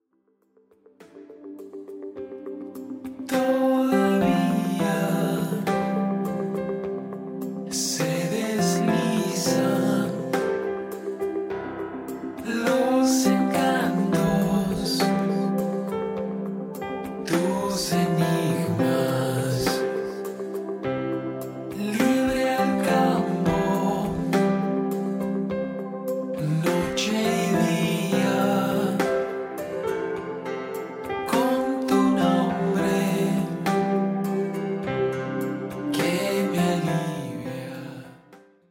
Grabado en casa.
Guitarras